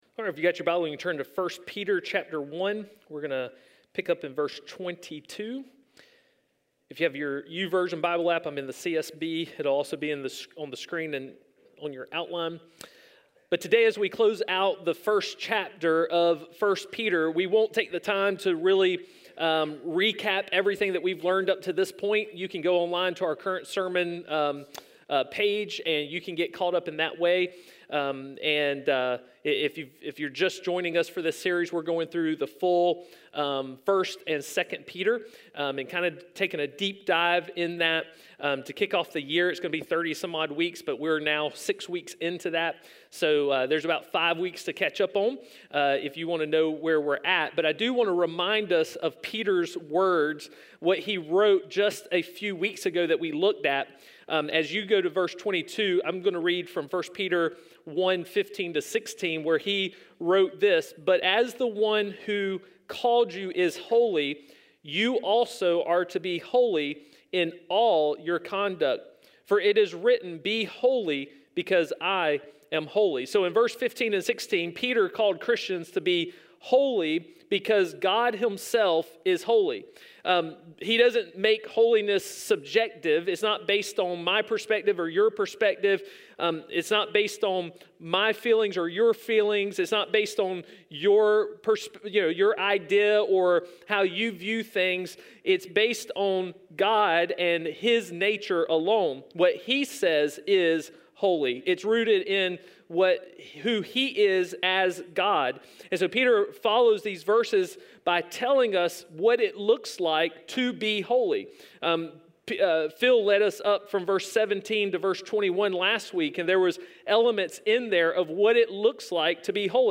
A message from the series "Foreigners."